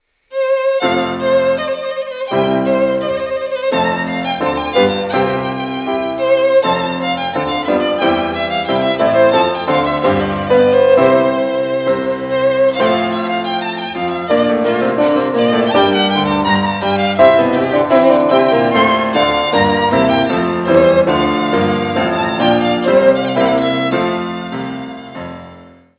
piano
violin)hu